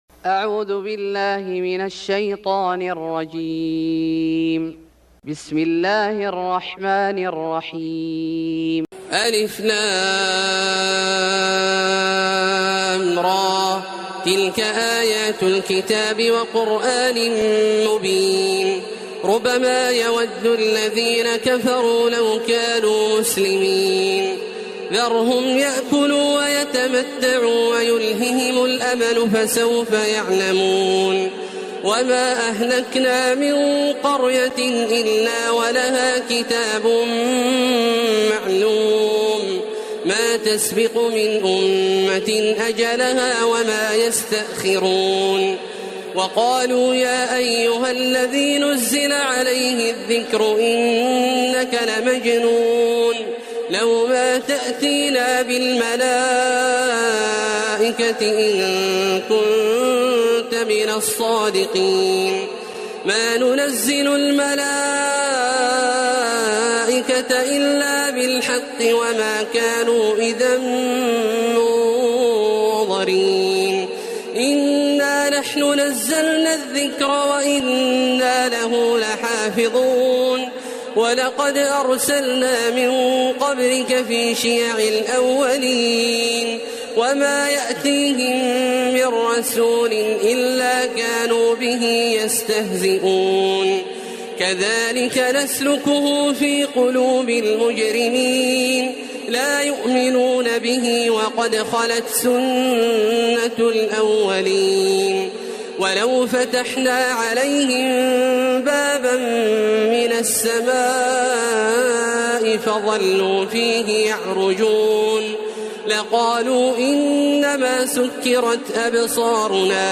سورة الحجر Surat Al-Hijr > مصحف الشيخ عبدالله الجهني من الحرم المكي > المصحف - تلاوات الحرمين